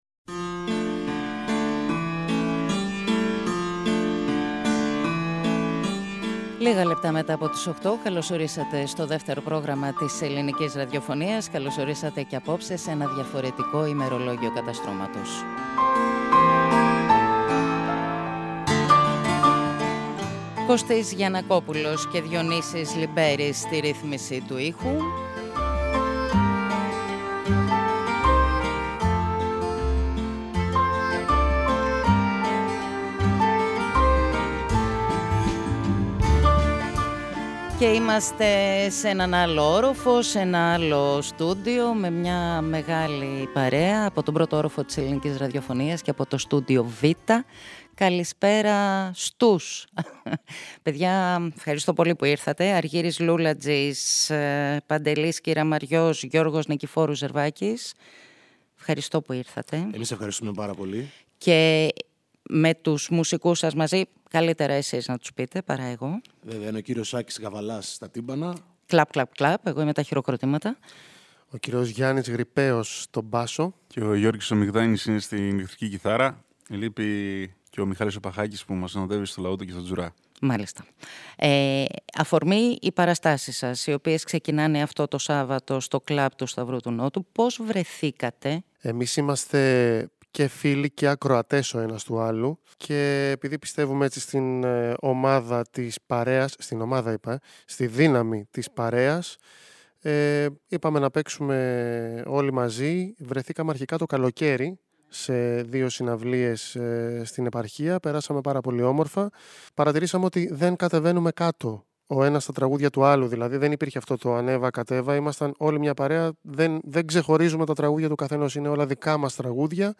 ένα ρυθμικό αλλά και μελωδικό live
Λύρα και φωνή
Ακουστική κιθάρα και φωνή
Τύμπανα
Ηλεκτρική κιθάρα